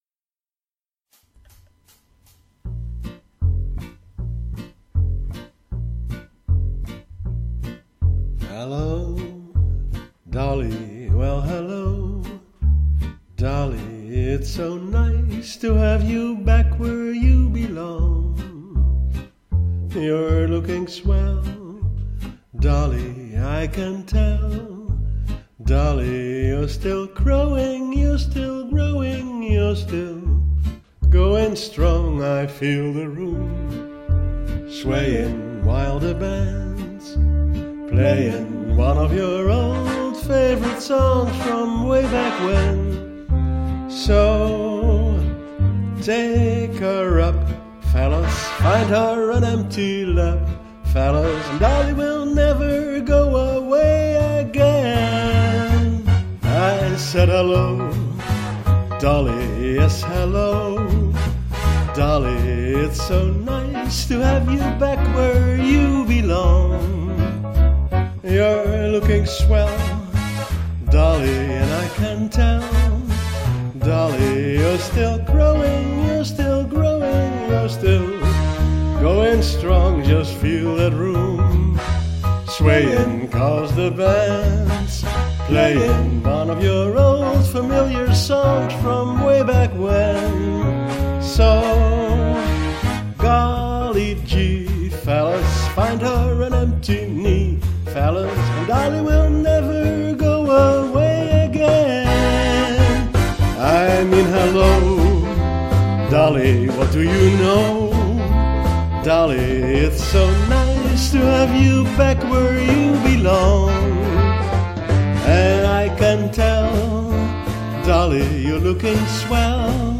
sung by me :-)
Goed gezongen!